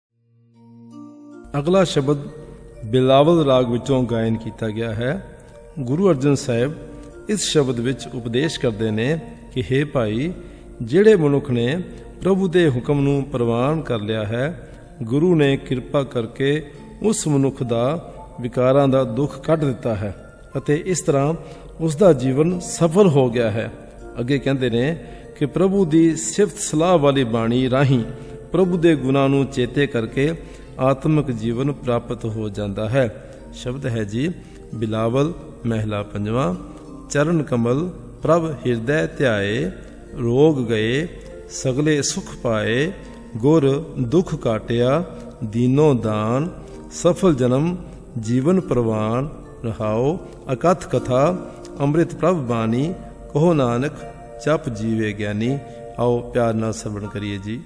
Sung in Raag Bilawal; Taals Keharwa,Teen Taal.